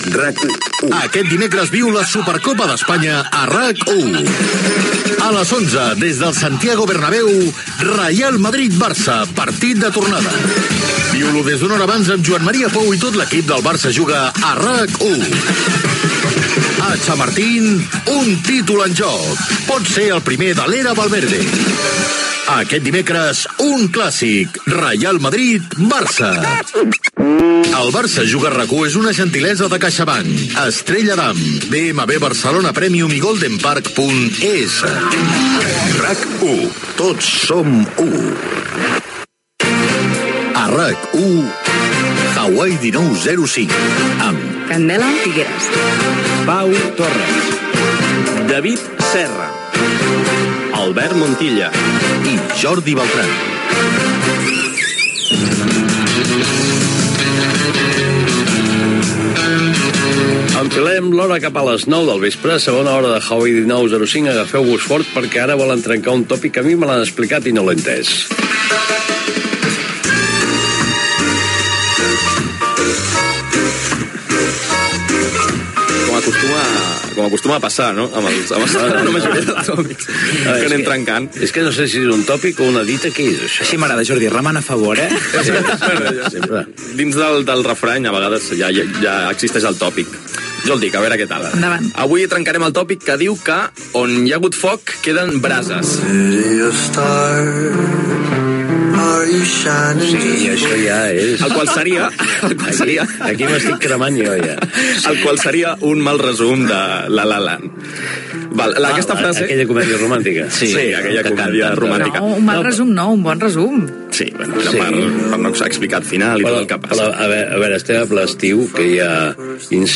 Indicatiu de la ràdio
careta del programa